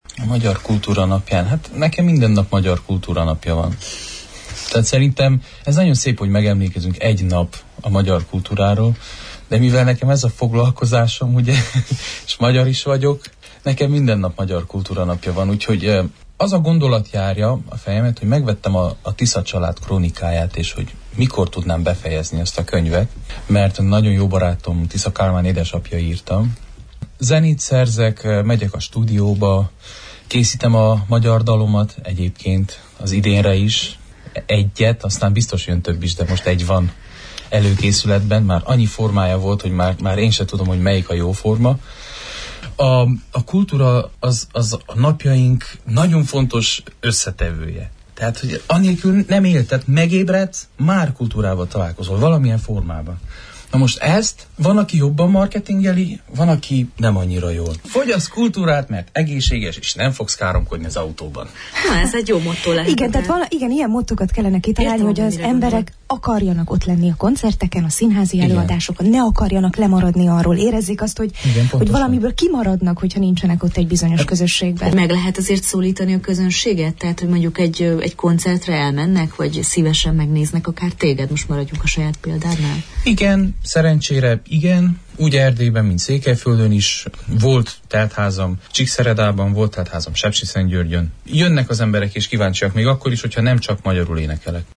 előadóművésszel beszélgettünk a magyar kultúra napja kapcsán.